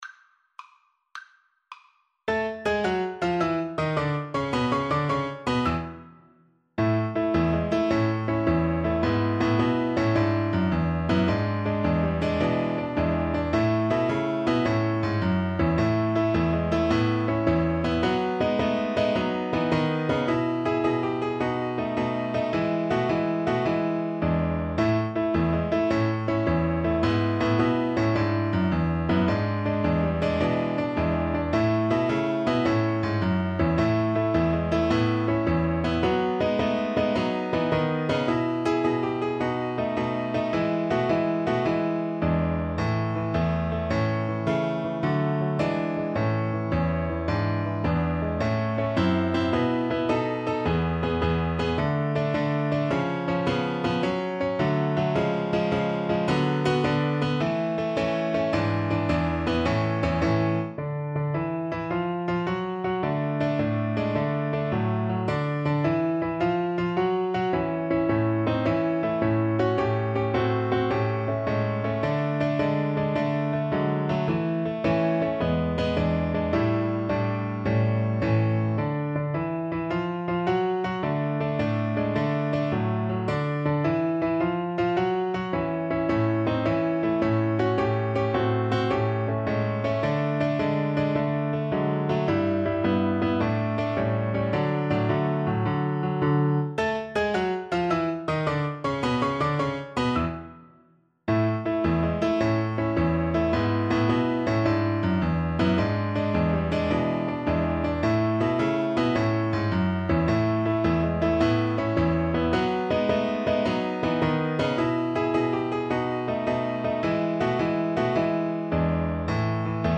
ViolinPiano
Allegro con brio = 160 (View more music marked Allegro)
6/8 (View more 6/8 Music)
Violin  (View more Intermediate Violin Music)
Classical (View more Classical Violin Music)
this song makes me feel happy!